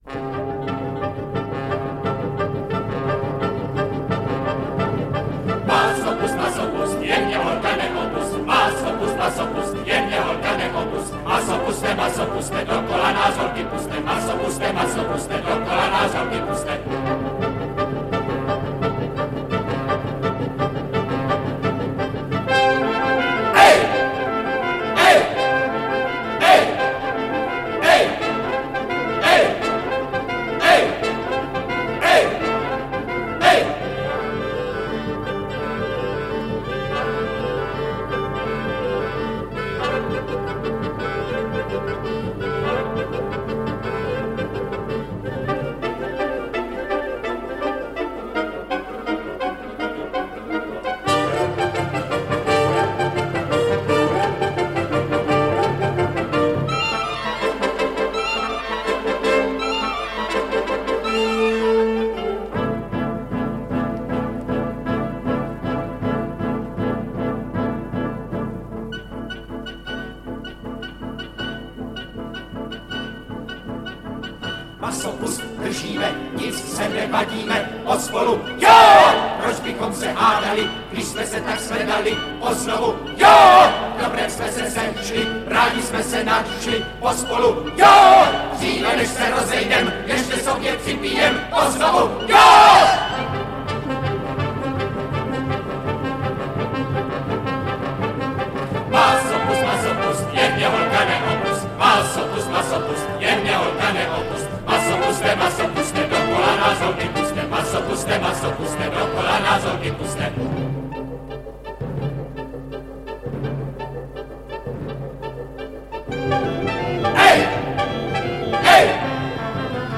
Audiobook
Audiobooks » Poetry